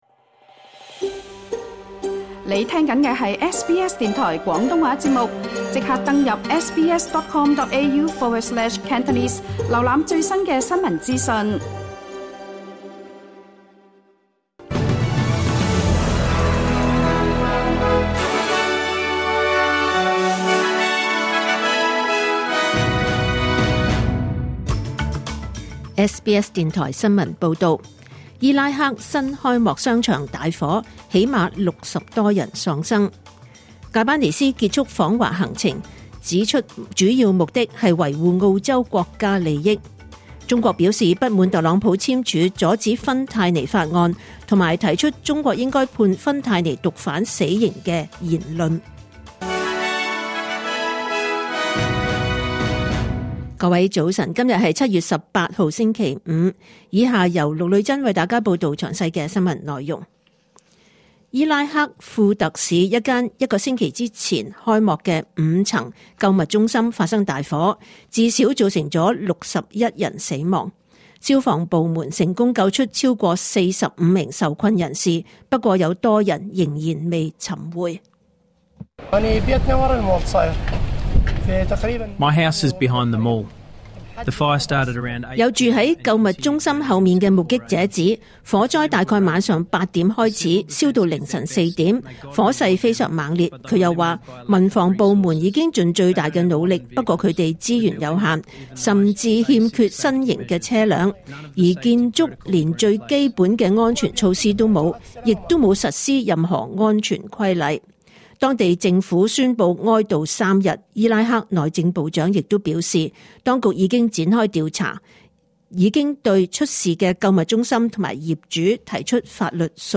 SBS廣東話九點半新聞報道